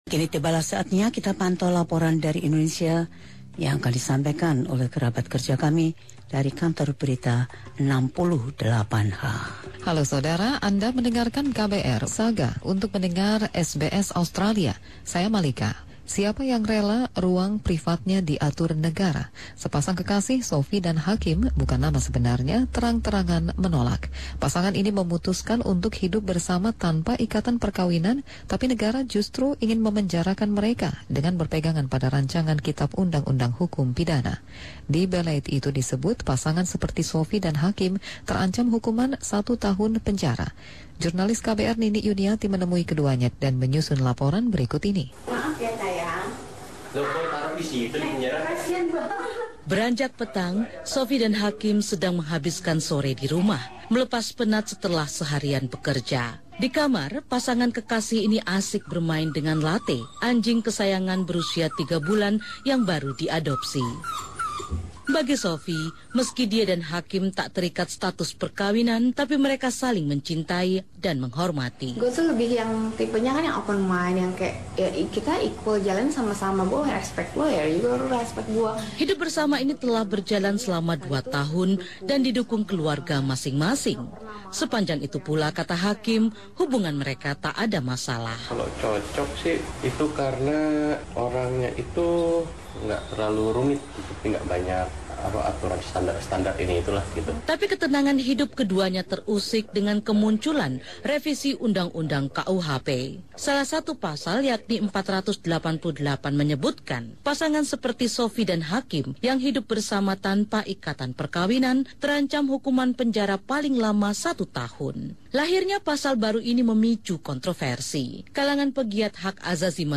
Koresponden kami di Jakarta, KBR 68H melaporkan bahwa dengan adanya usulan tentang perubahan KUHP, orang di Indonesia yang hidup bersama tanpa nikah akan menghadapi kesulitan secara hukum.